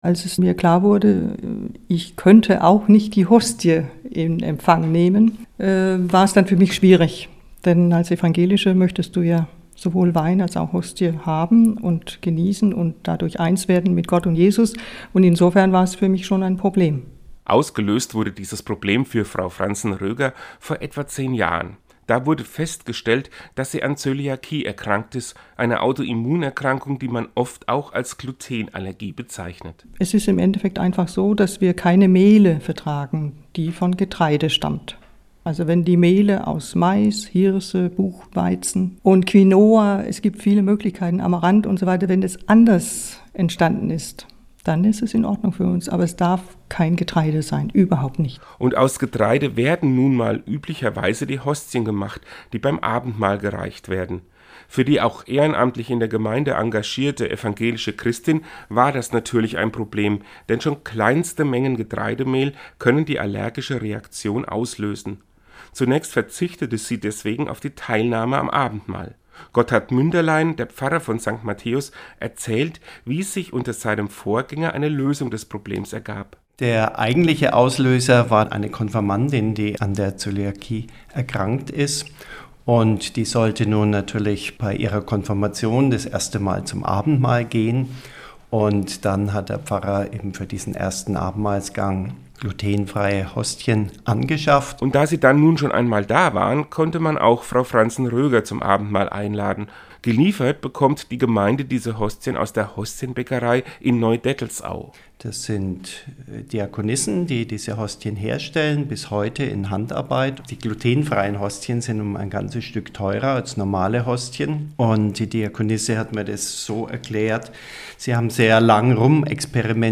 Seinen Radiobeitrag hören Sie hier: Quelle: Einzugsgebiet Aschaffenburg Zurück Teilen Downloads 8,2 MB Glutenfreies Abendmahl 17.10.2019 | © bv